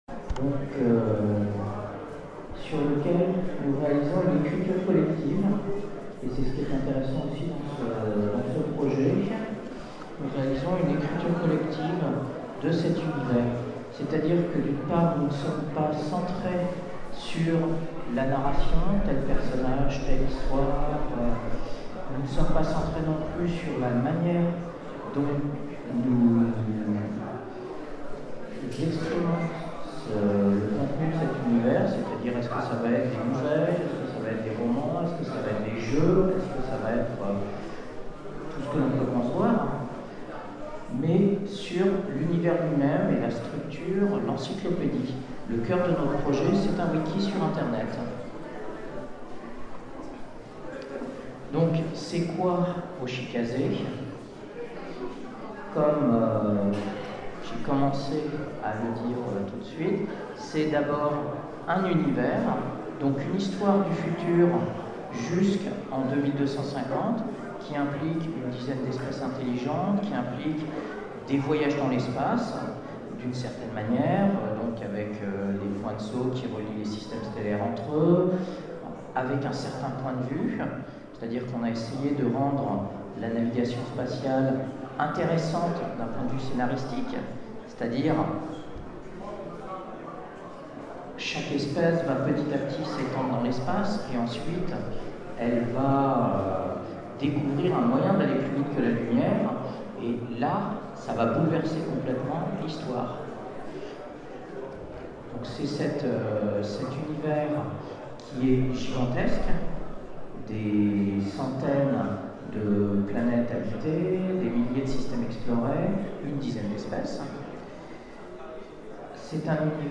Convention 2012 : Conférence Hoshikaze 2250 – présentation